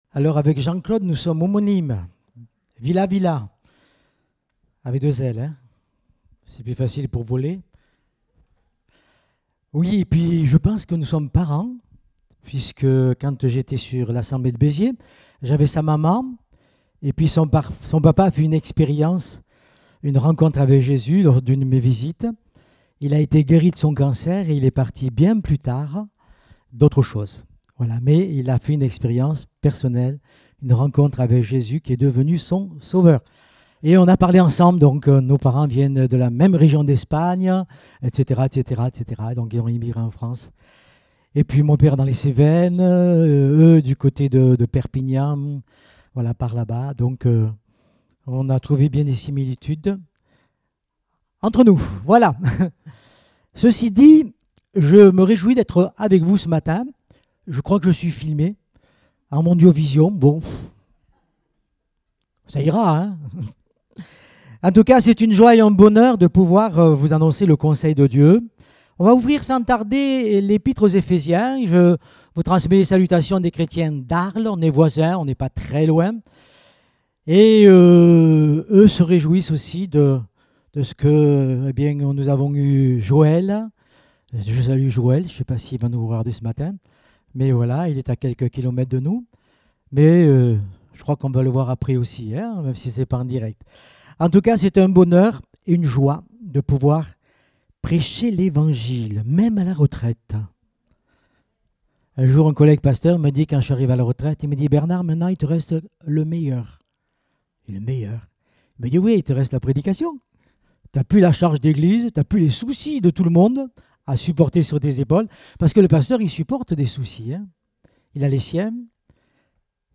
Date : 9 juillet 2023 (Culte Dominical)